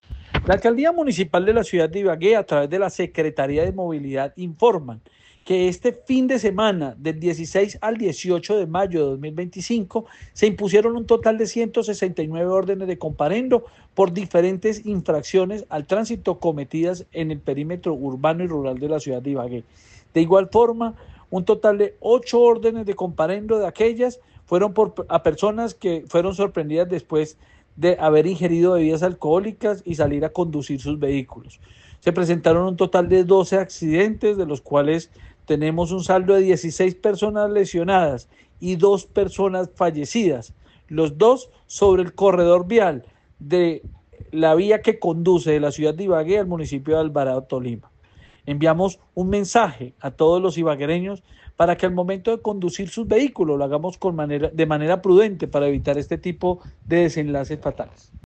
Escuche las declaraciones de Ricardo Rodríguez, secretario de Movilidad: